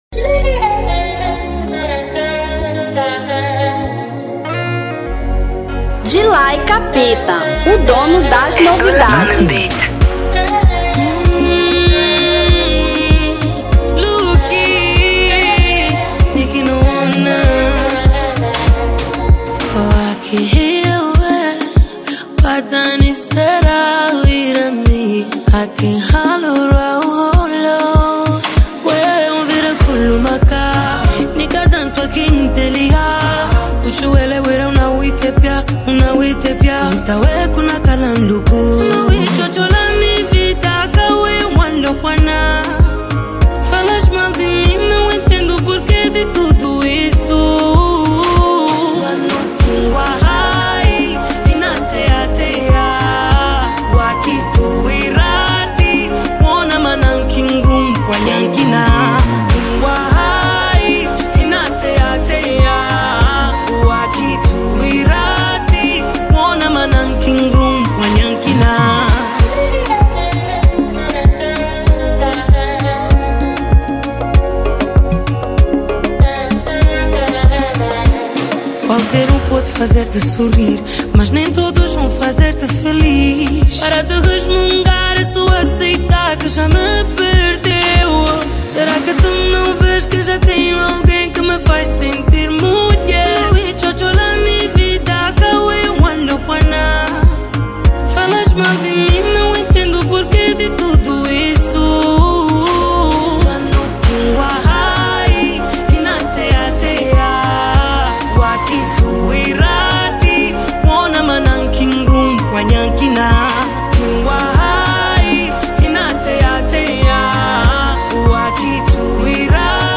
Afro Beat 2025